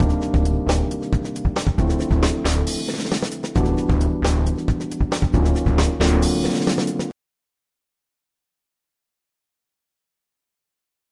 节拍120 bpm groove " 节拍组合4bo
描述：爵士乐的放克，有真正的鼓和贝斯，有键盘。
Tag: 120-BPM 节拍 芬克 爵士